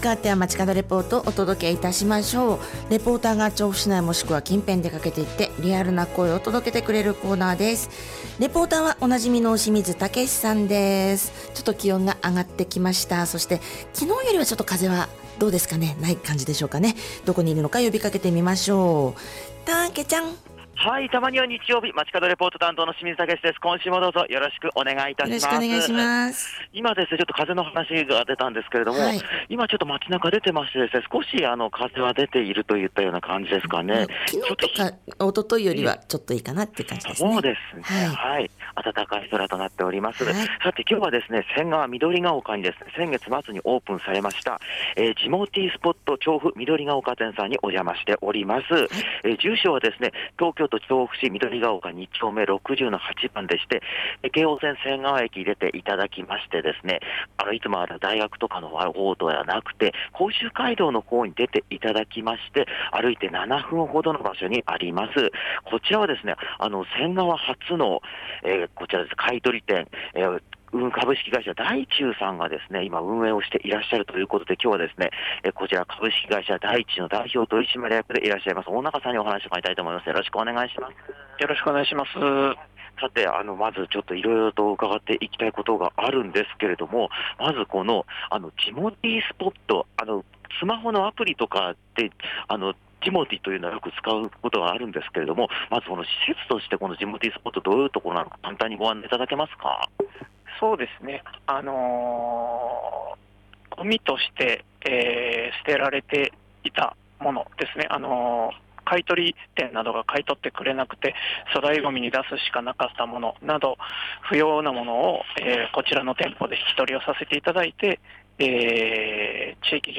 前日の風少し残っている印象の日曜日にお届けした本日の街角レポートは、 仙川・緑ヶ丘に2月27日にオープンされた『ジモティースポット調布緑ヶ丘店』さんからレポートです！